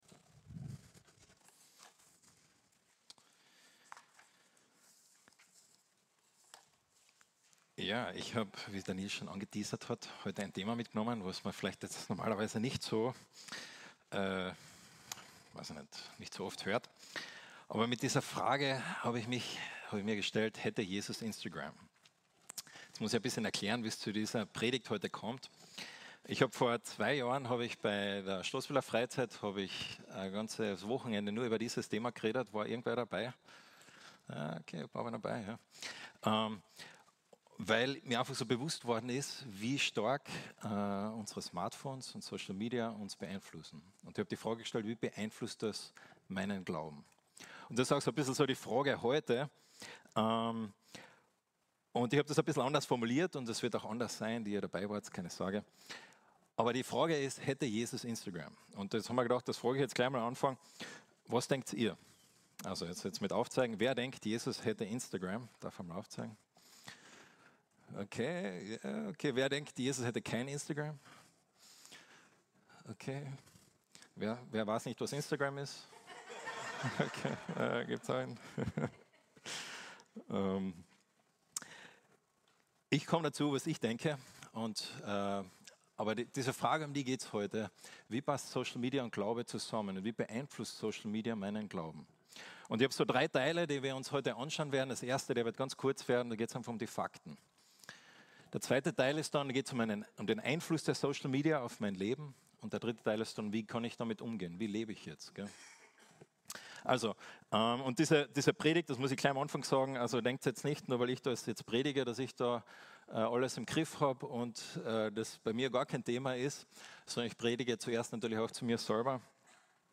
Eine predigt aus der serie "Einzelpredigten 2024."